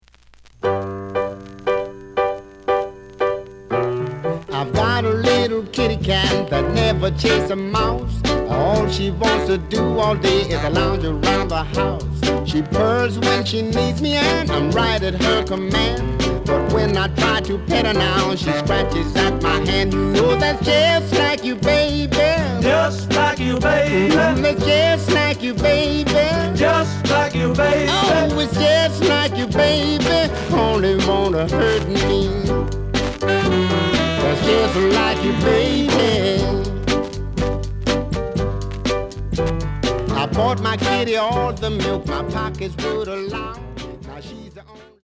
The audio sample is recorded from the actual item.
●Genre: Rhythm And Blues / Rock 'n' Roll
Some noise on parts of B side.)